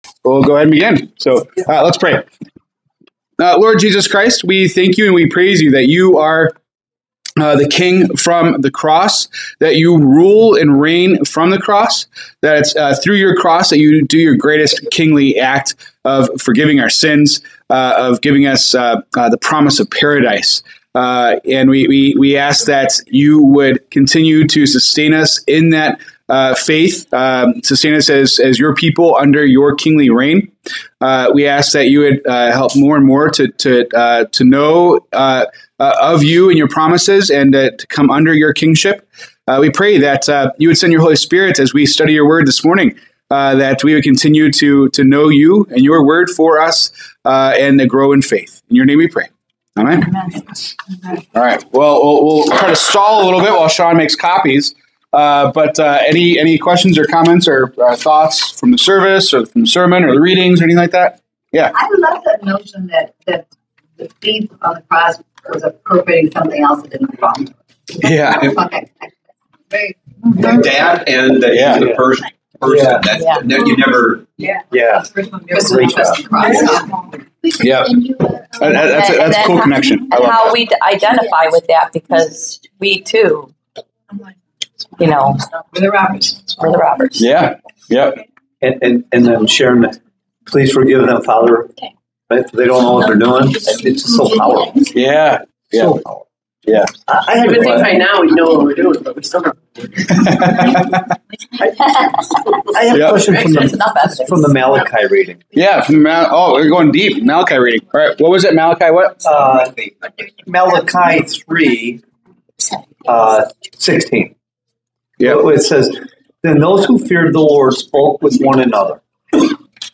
November 23, 2025 Bible Study
The one where we don't talk about Revelation! Our discussion got carried away with talking about the Scripture readings from church, the thief on the cross, Baptism, souls and bodies, the image of God, and more.